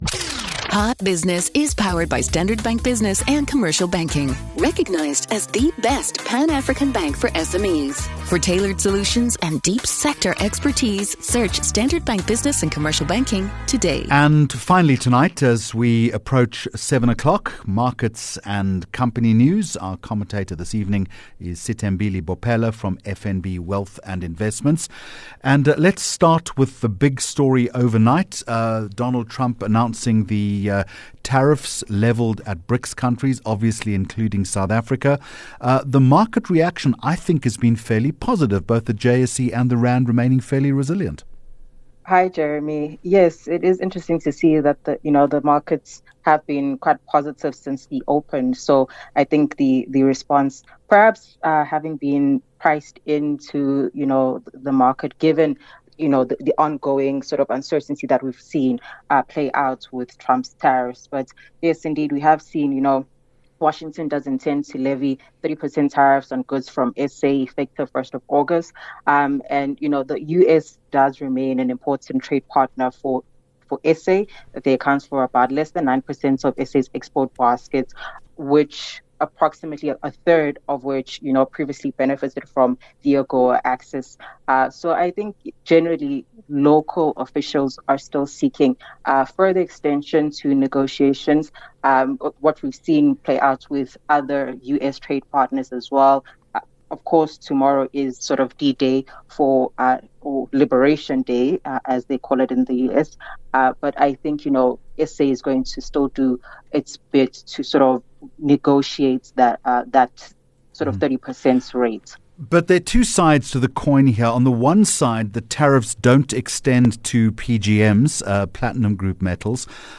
8 Jul Hot Business Interview